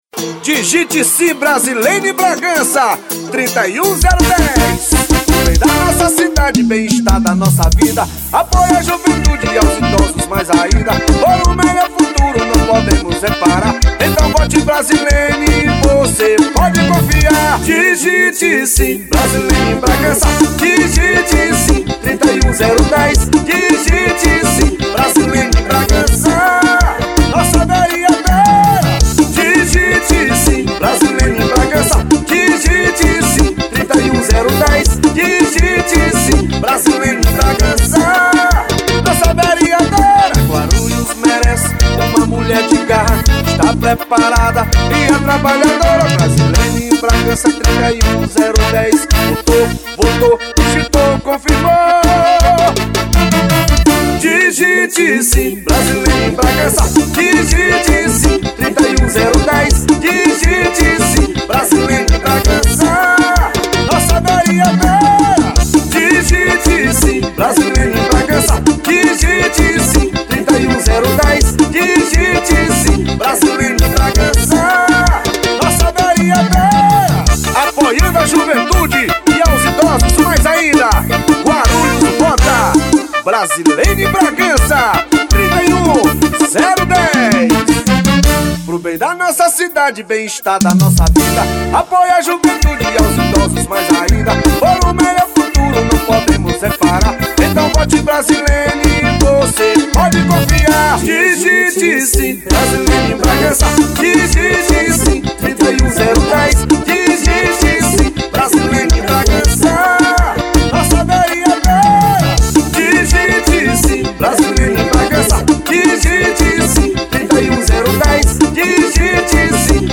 EstiloJingles / Spots